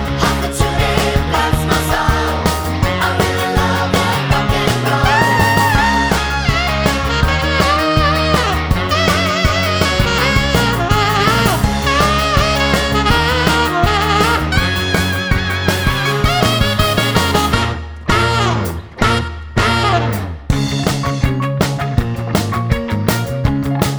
no Backing Vocals Soundtracks 3:02 Buy £1.50